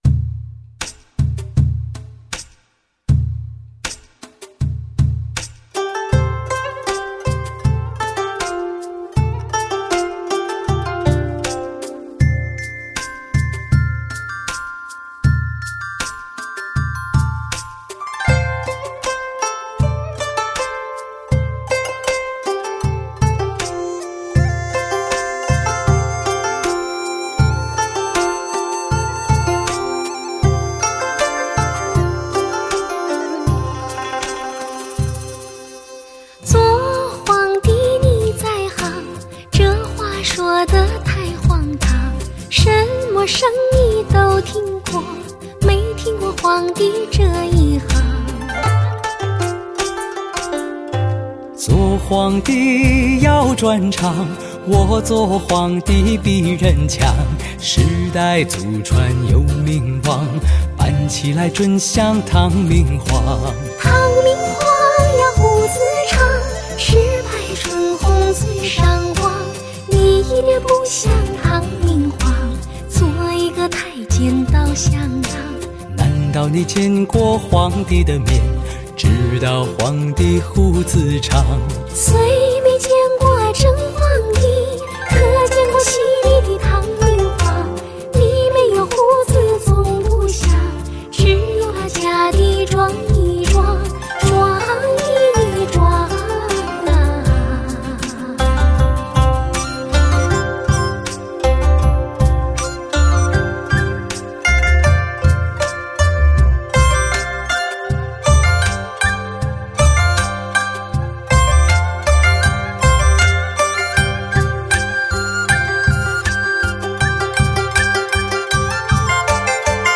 男女对唱经典
淳朴流畅，明快抒情，雅俗共赏，怡情悦性，传承经典